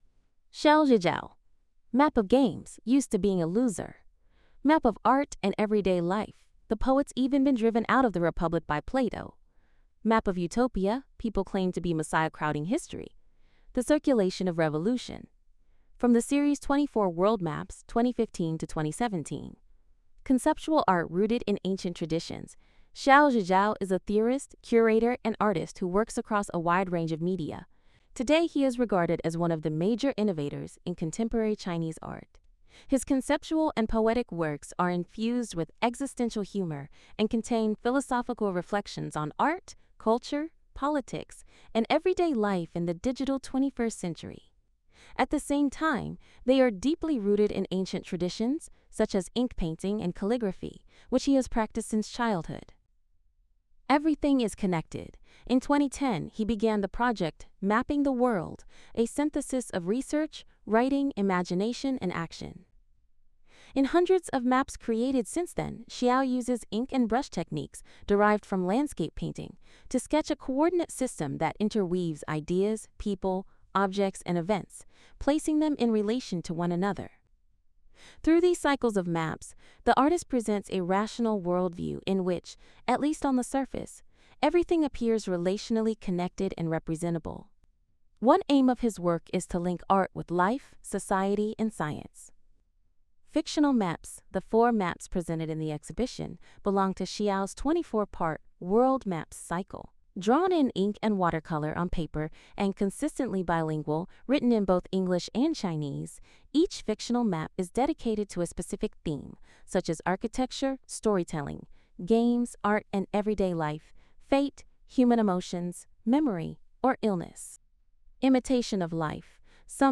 Hinweis: Die Audiotranskription ist von einer KI eingesprochen.